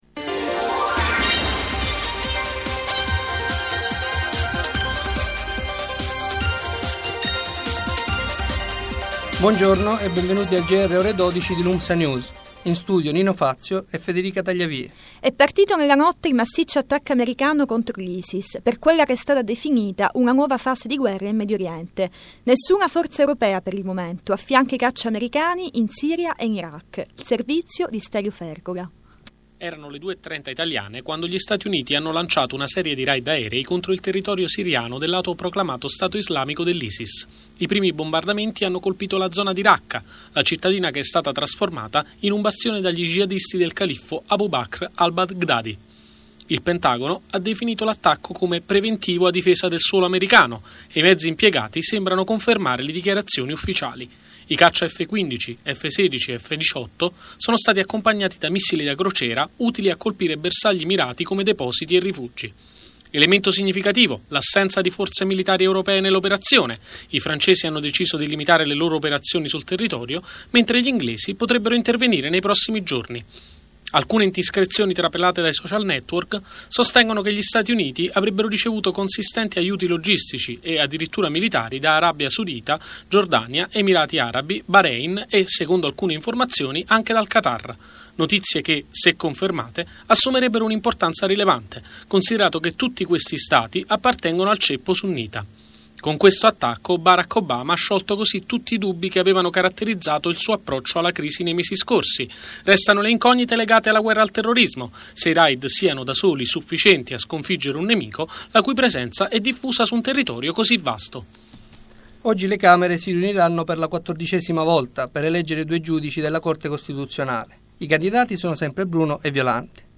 Gr ore 12 del 23 Settembre 2014
In studio: